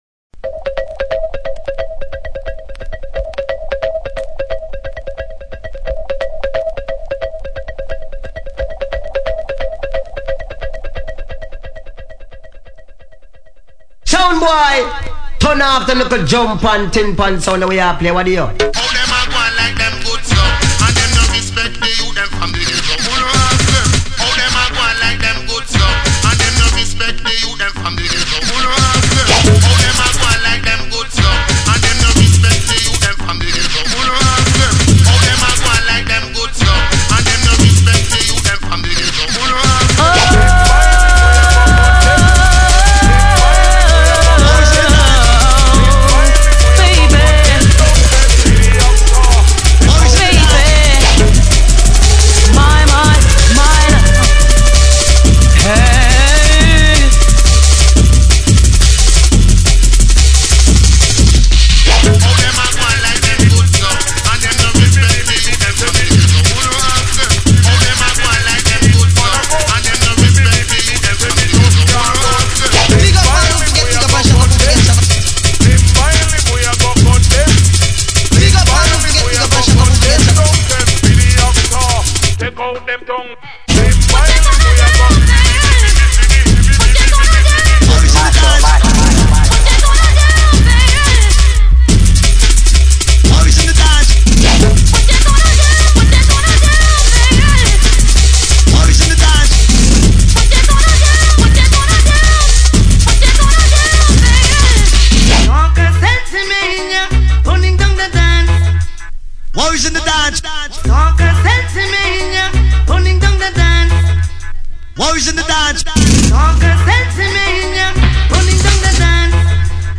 22 Jungle Licks in da Mix.